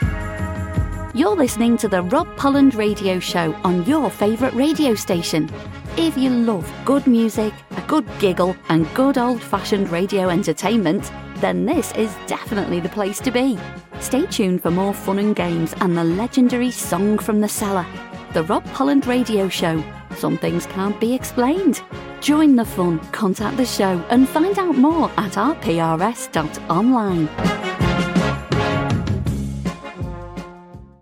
It’s good old-fashioned radio entertainment!